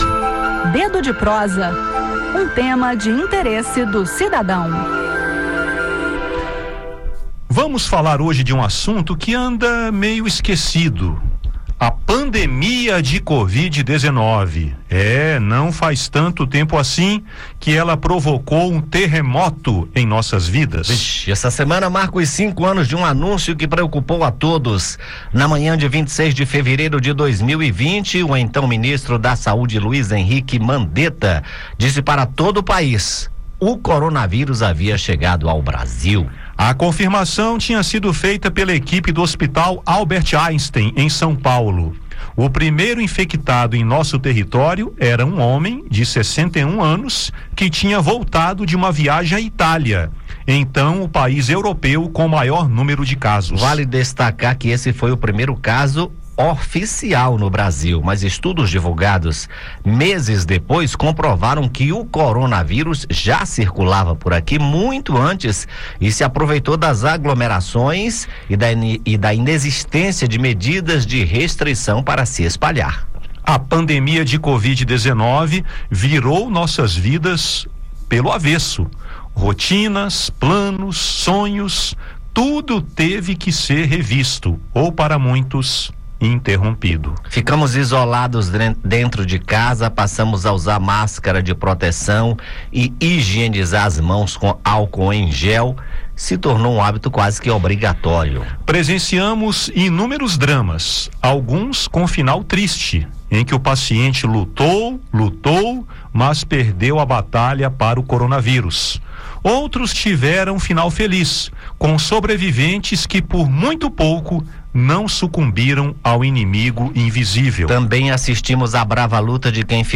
Mas atenção: embora não vivamos mais uma pandemia, o coronavírus continua circulando. Apenas em 2025 já foram notificados cerca de 100 mil casos, com aproximadamente 500 mortes. Confira no bate-papo e previna-se.